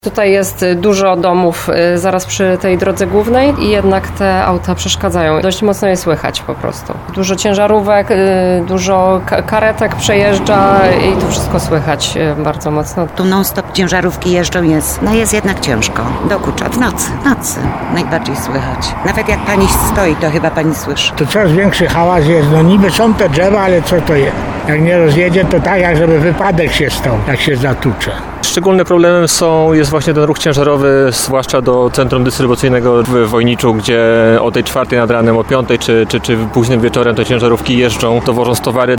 Tak mieszkańcy podtarnowskich Mikołajowic i Łukanowic, których domy znajdują się w pobliżu 'starej czwórki” opisują swoją codzienność.